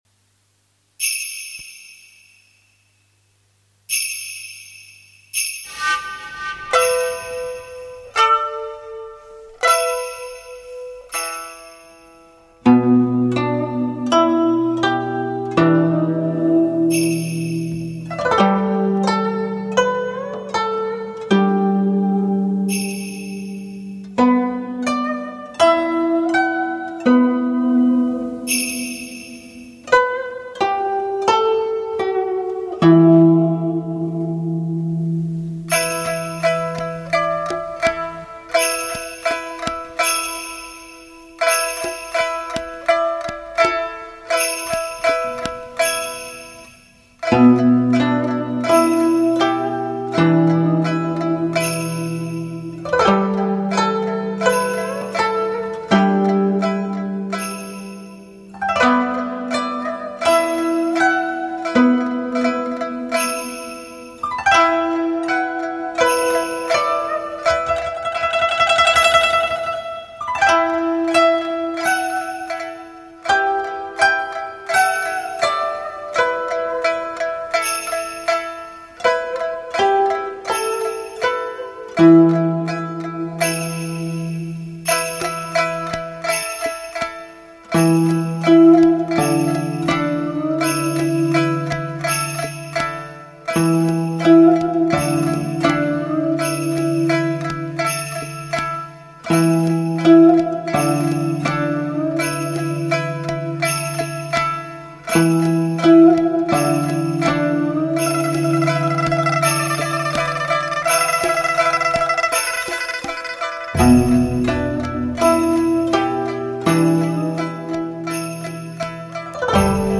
征服最挑剔的耳朵 创意展示传统中乐艺术观心之美